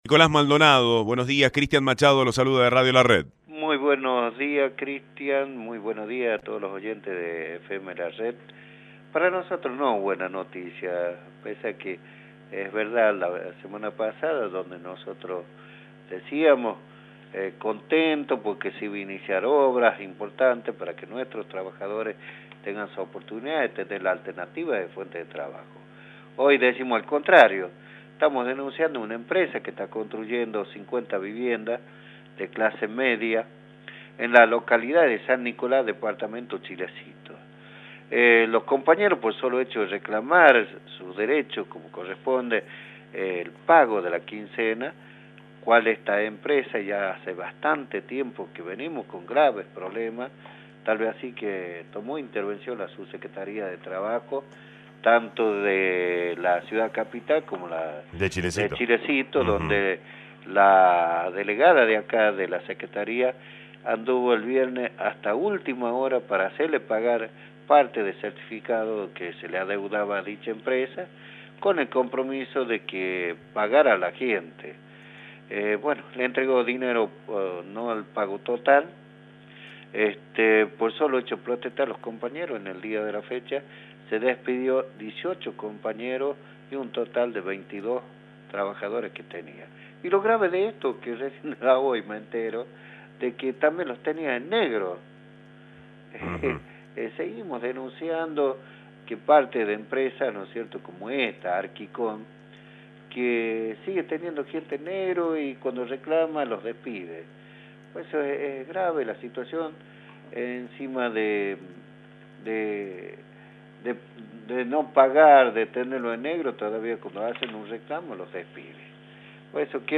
por Radio La Red